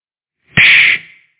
FX - HIT IMPACT - Retro Videogame
16-bit 8-bit arcade clap clip fight game hit sound effect free sound royalty free Gaming